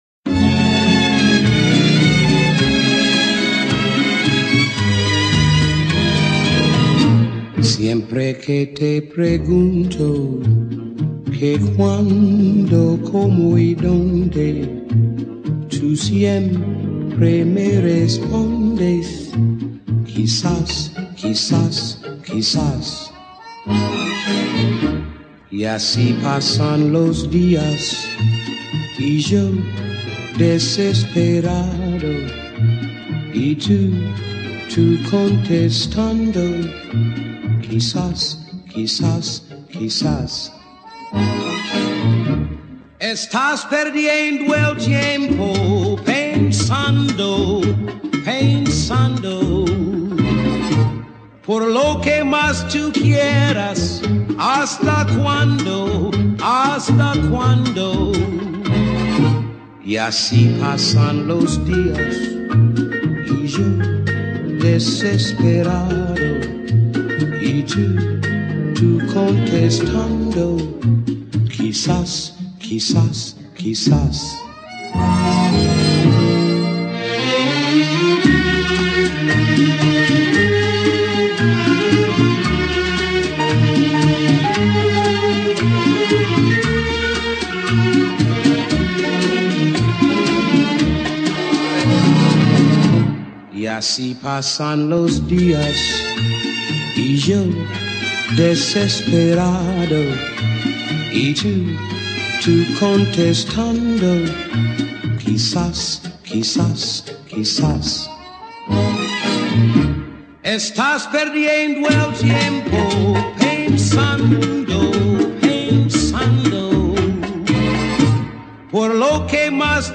Spanish song